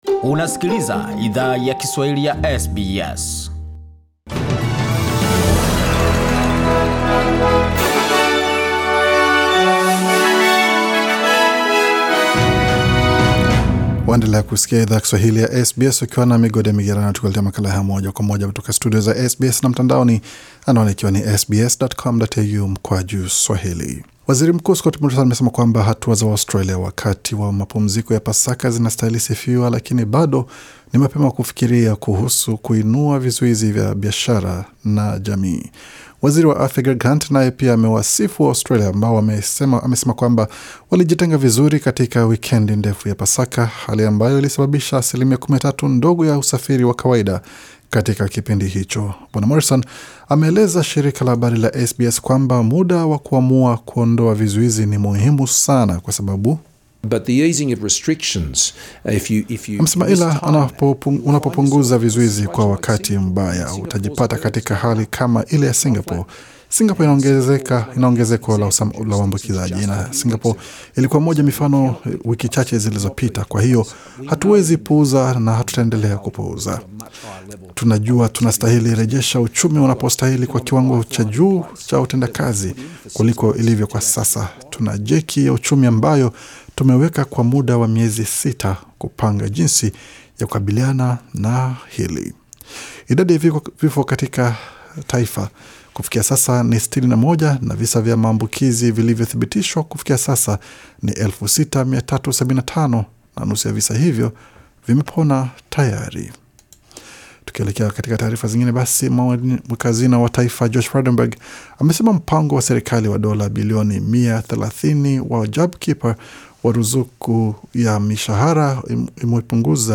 Taarifa za habari:Maelfu yawa Australia kufanyiwa vipimo vya coronavirus